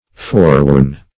Forewarn \Fore*warn"\ (f[=o]r*w[add]rn"), v. t. [imp. & p. p.